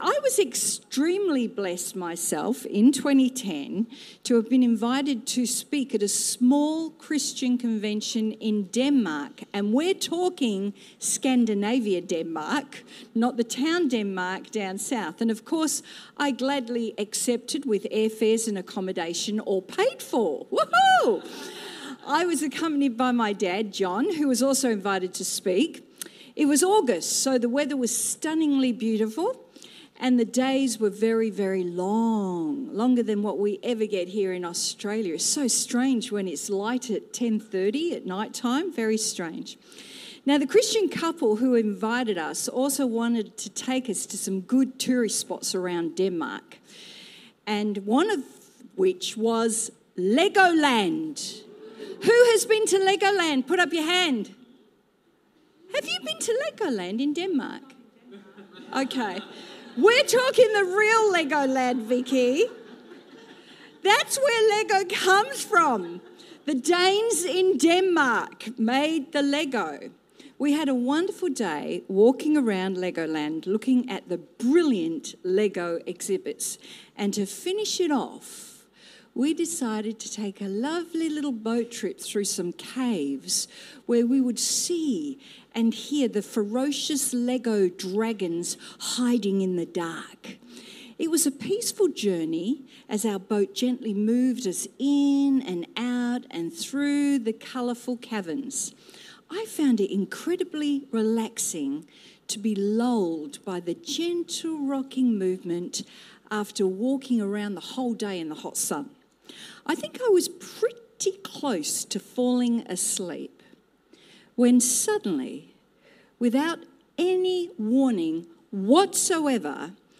Sermon Transcript: GET ME OUT!!